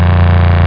_moteur2.mp3